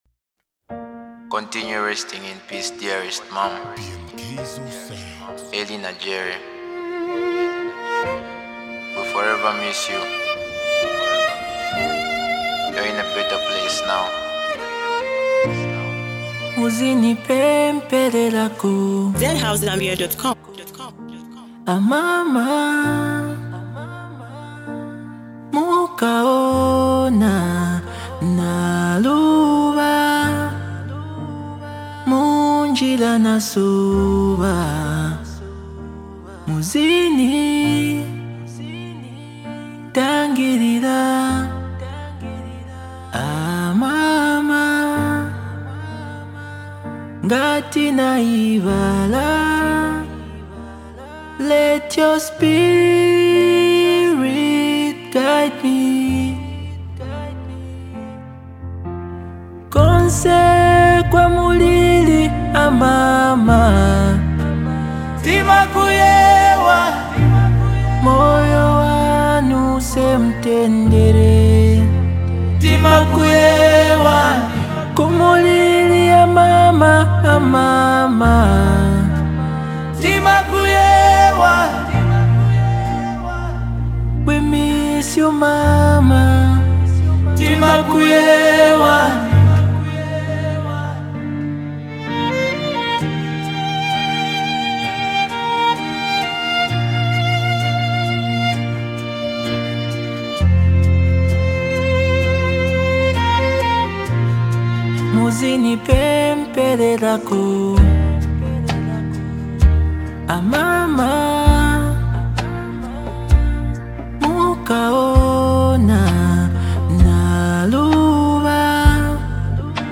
a soulful tribute